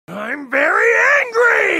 im very angry goku Meme Sound Effect
Category: Anime Soundboard
im very angry goku.mp3